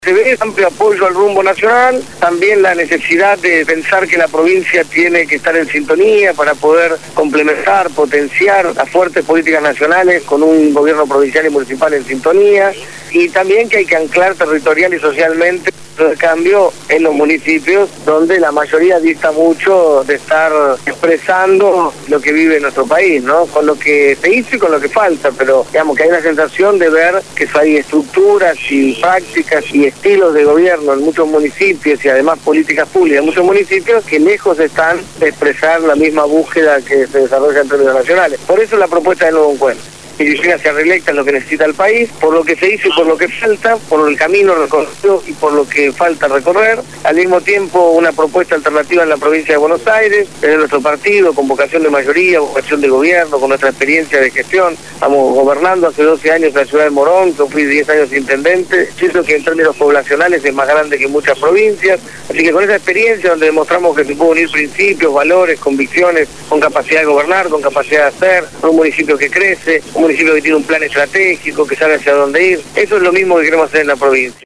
Lo dijo Martín Sabbatella, candidato a gobernador bonaerense por Nuevo Encuentro (cuya boleta llevará la fórmula presidencial Cristina Fernandez-Amado Boudou) en «Desde el Barrio».